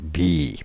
Description French pronunciation of « Billy »